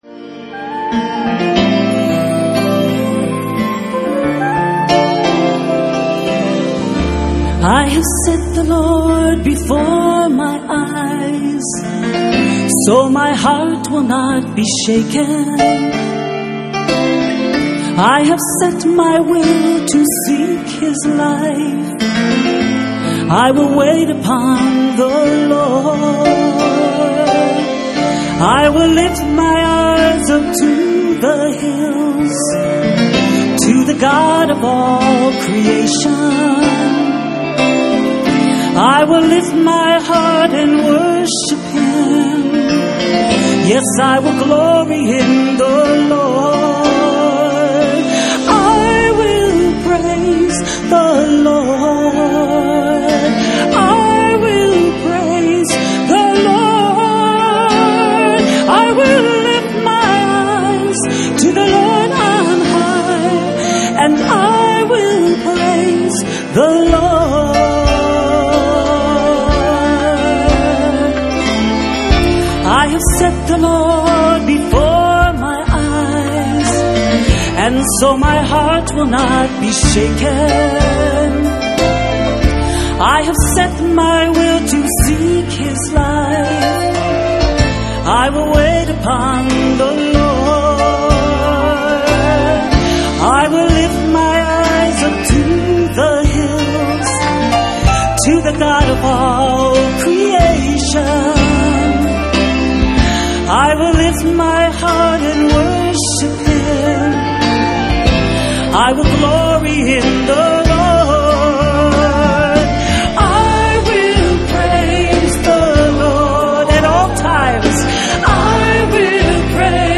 In this sermon, the speaker discusses the restoration of Israel and the role it plays in the end times. He emphasizes the importance of the Jewish people turning to Jesus as a sign of the approaching end.